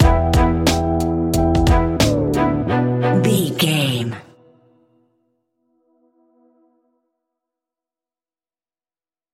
West Coast Urban Stinger.
Aeolian/Minor
chilled
laid back
groove
hip hop drums
hip hop synths
piano
hip hop pads